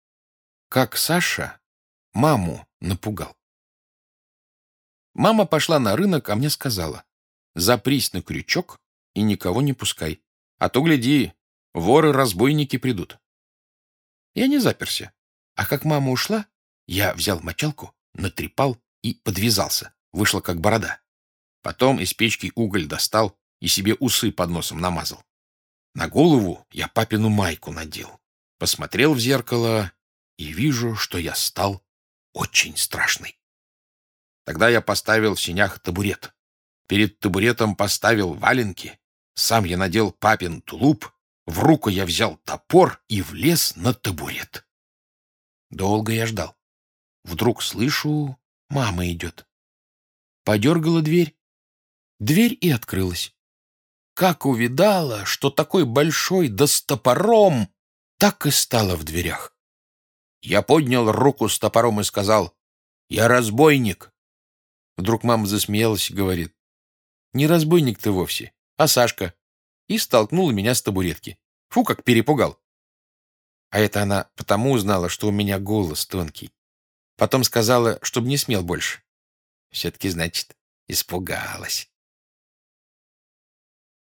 Аудиорассказ «Как Саша маму напугал»